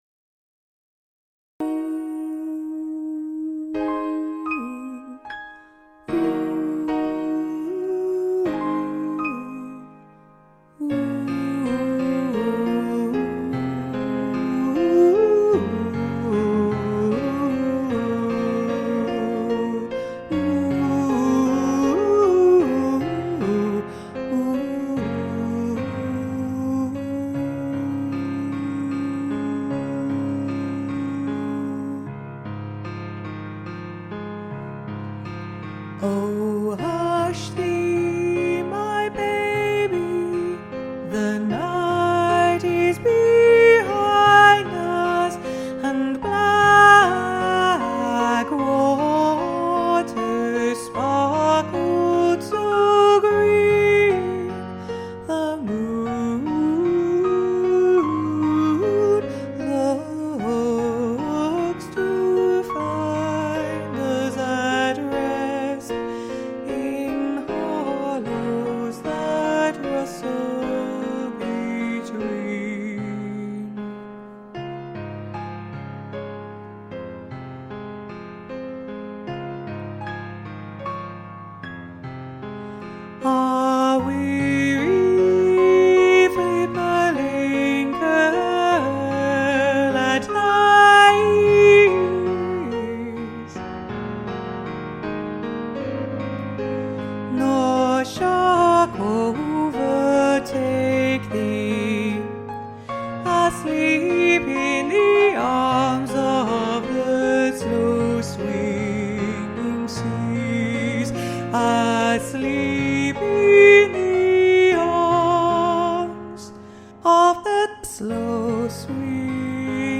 Seal_Lullaby_The_Alto.mp3